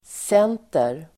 Uttal: [s'en:ter]